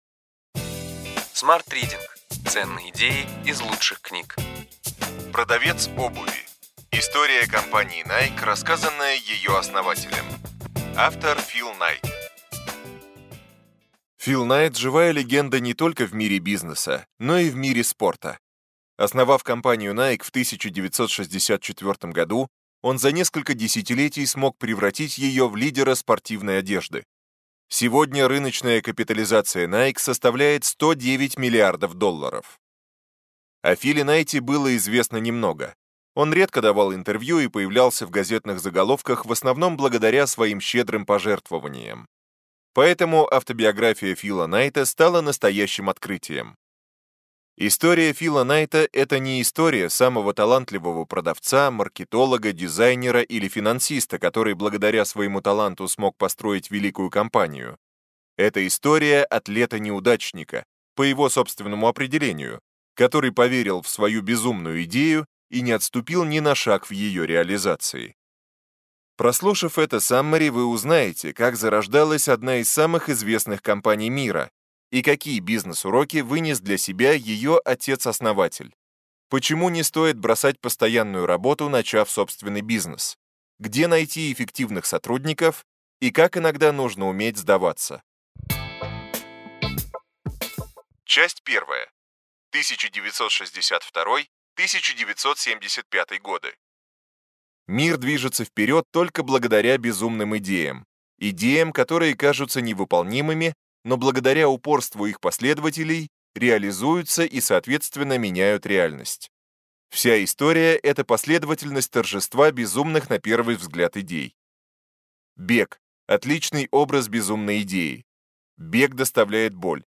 Аудиокнига Ключевые идеи книги: Продавец обуви.